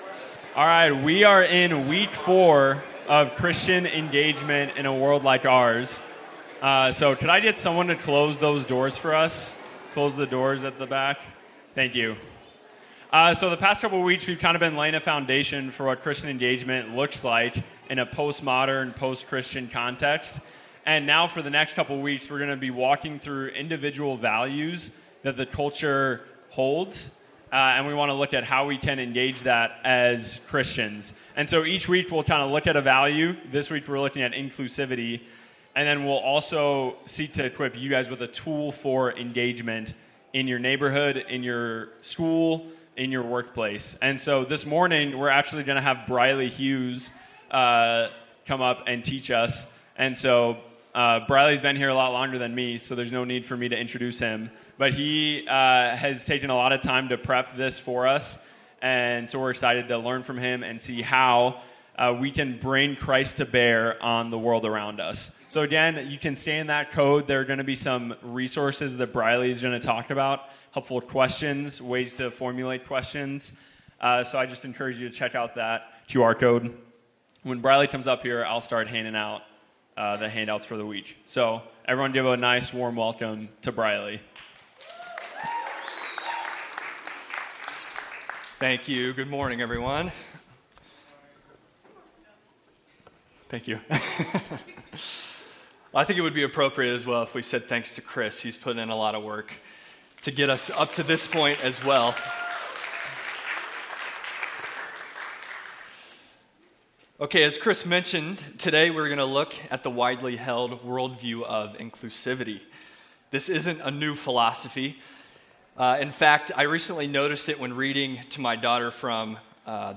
Engaging Inclusivity Gospel Grace Church Sermon Audio podcast